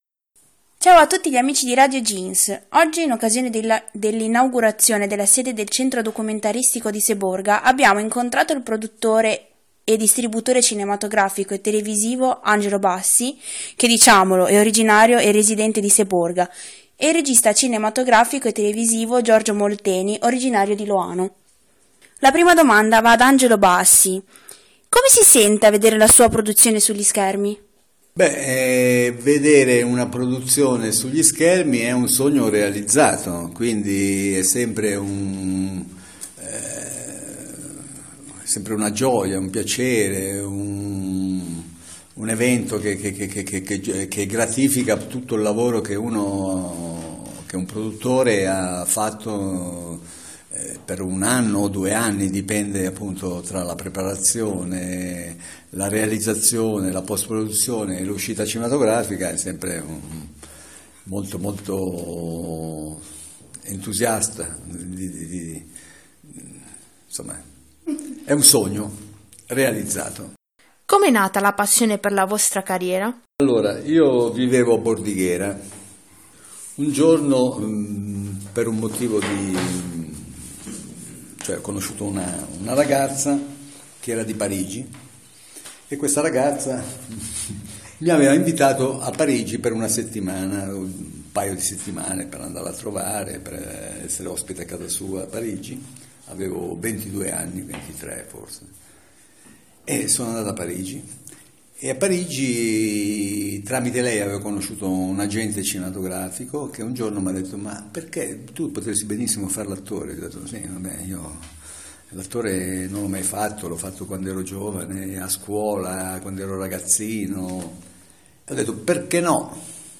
intervista
In occasione dell’innagurazione del centro Documentaristico di Seborga